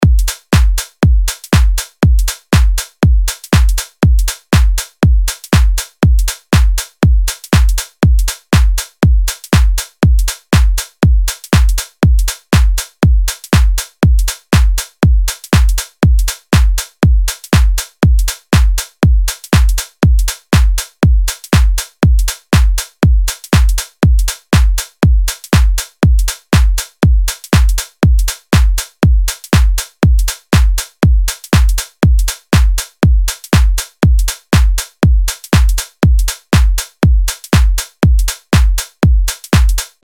LP 204 – DRUM LOOP – HOUSE – 120BPM